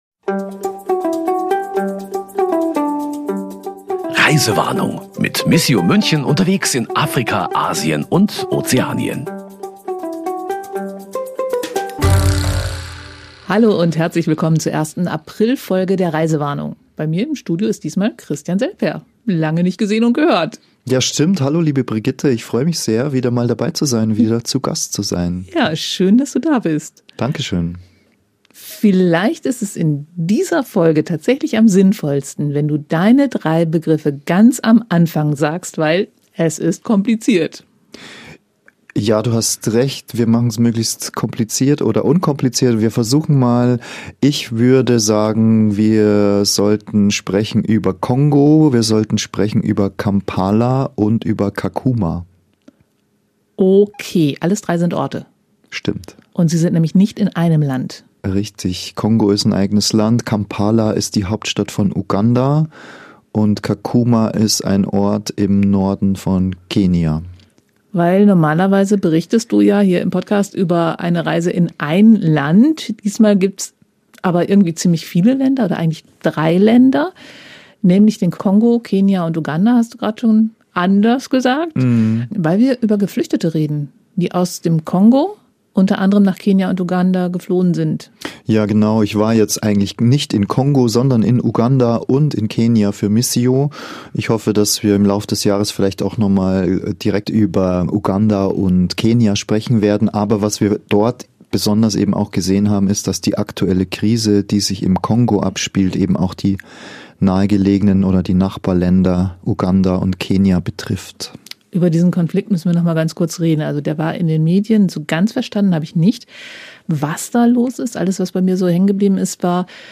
In „Reisewarnung“ erzählen die Reporterinnen und Reporter, was sie auf ihren Reisen erleben. Es geht um Autopannen und verspätete Flugzeuge, um schlaflose Nächte unterm Moskitonetz, und das eine oder andere Experiment im Kochtopf ist auch dabei.